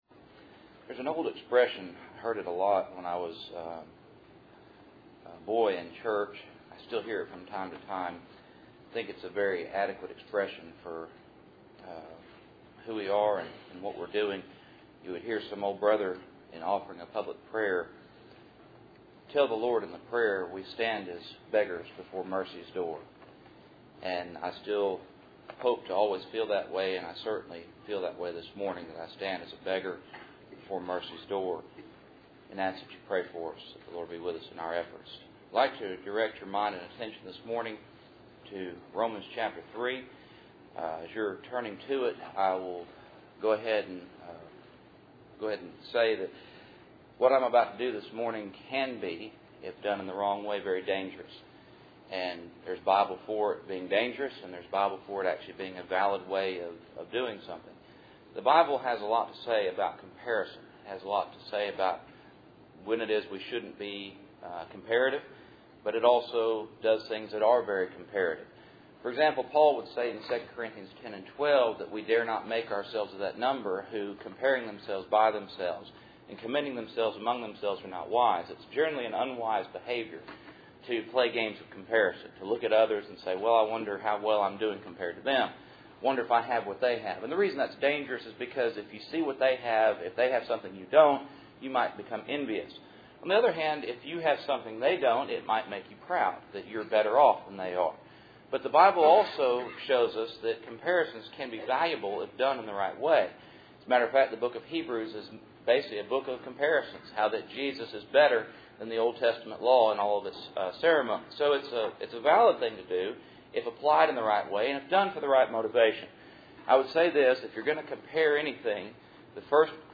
Romans 3:1-8 Service Type: Cool Springs PBC Sunday Morning %todo_render% « What is God’s Foolishness and Weakness?